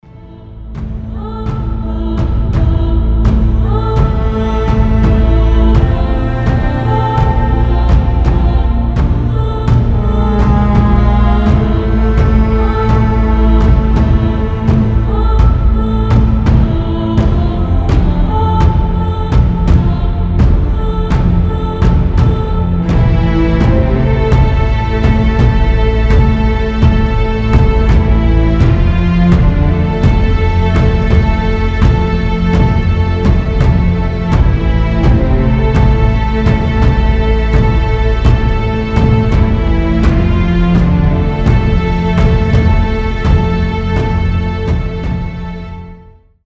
саундтреки
без слов
инструментальные
оркестр
эпичные